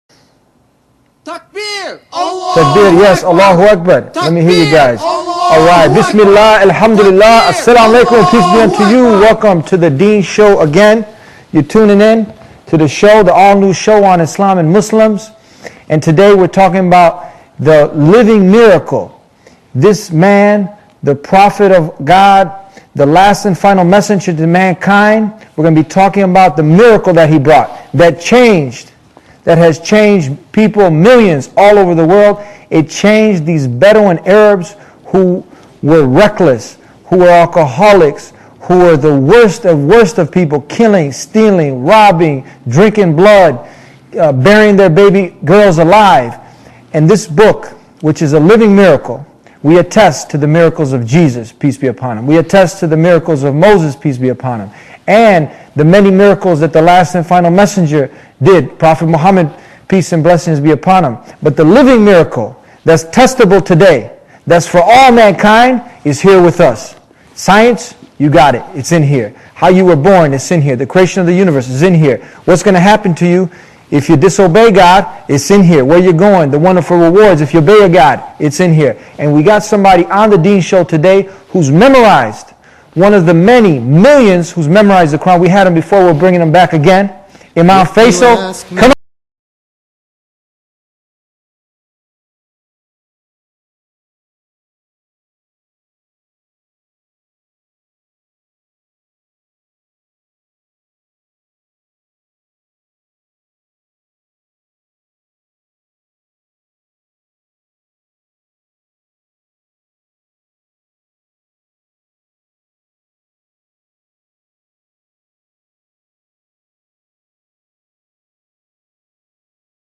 Interview with The Qur’an – The Deen Show